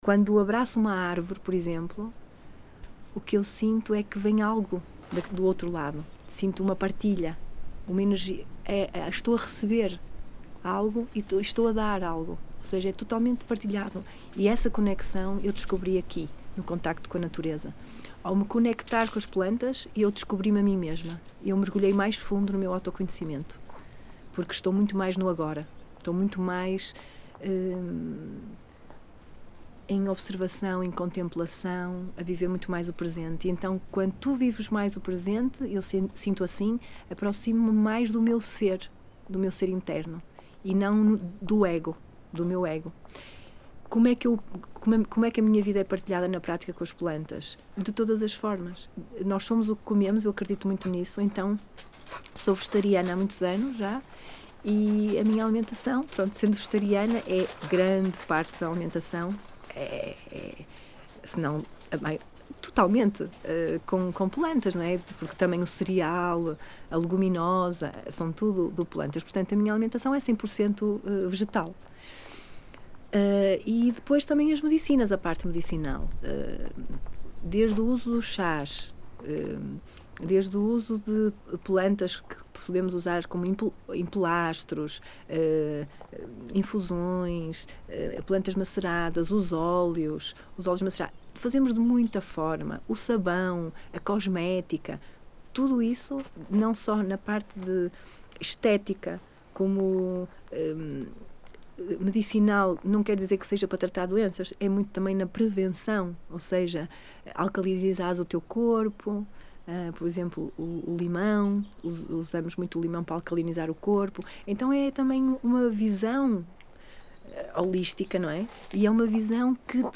Várzea de Calde e Cabrum, primavera de 2019.
Tipo de Prática: Inquérito Oral